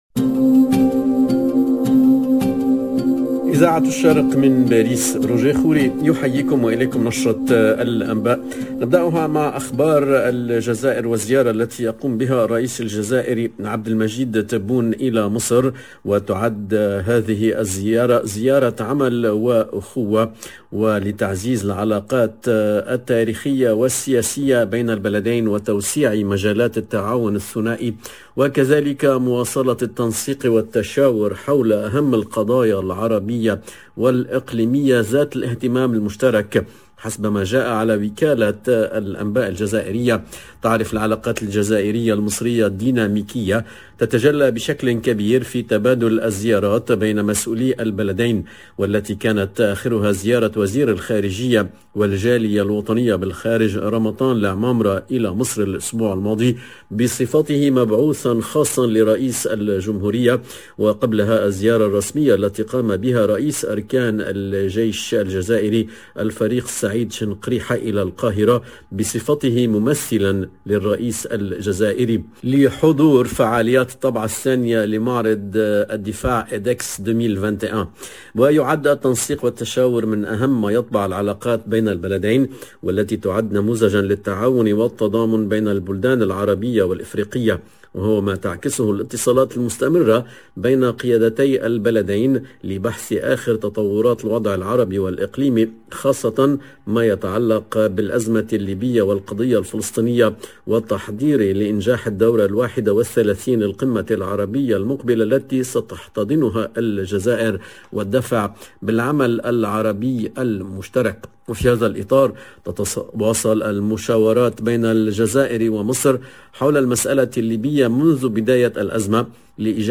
LE JOURNAL DE MIDI 30 EN LANGUE ARABE DU 24/01/22